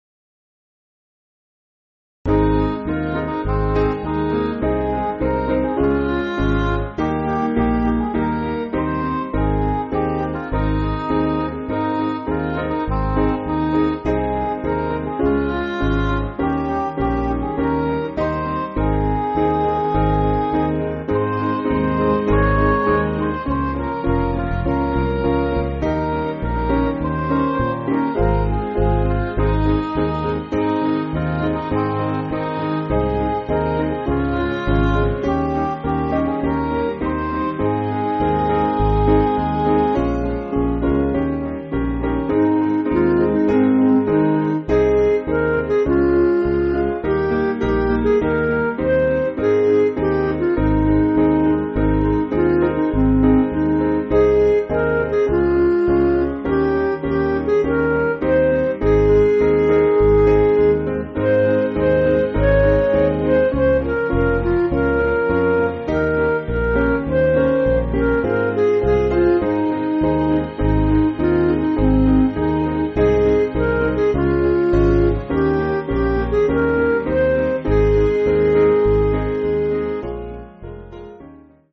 Piano & Instrumental
Midi